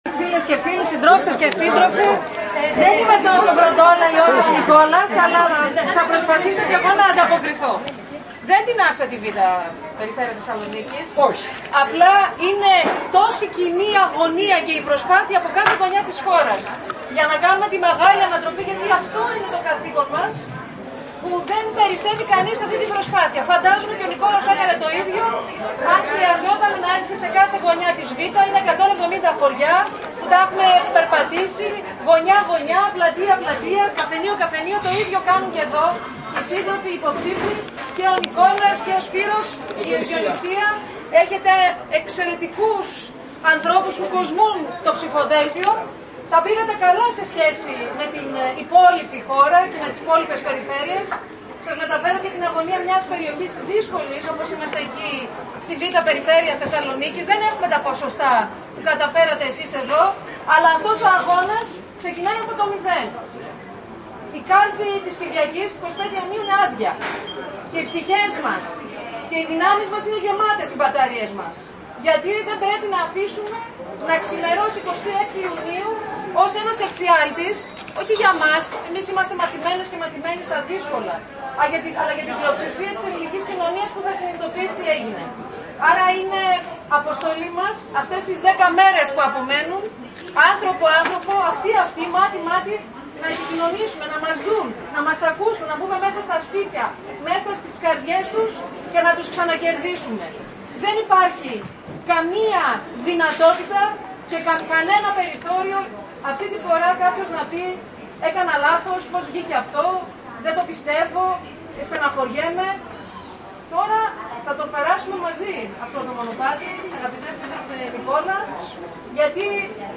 Ομιλία